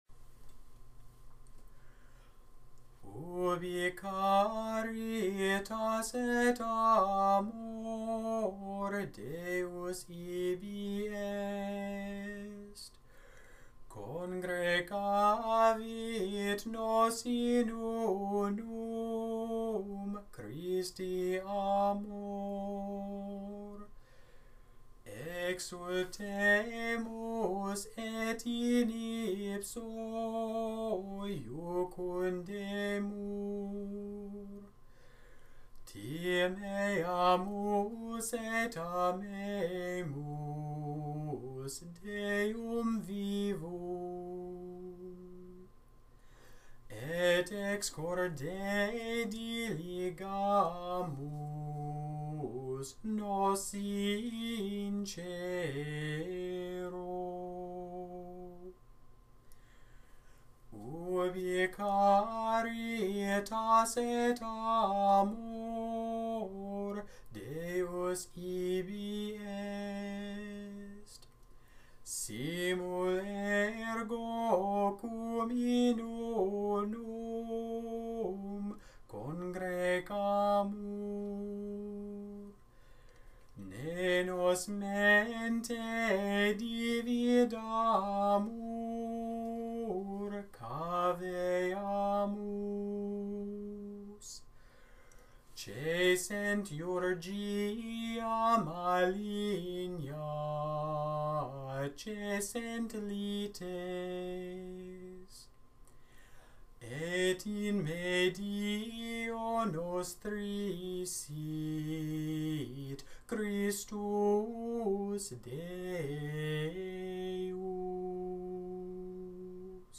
Gregorian, Catholic Chant Ubi Caritas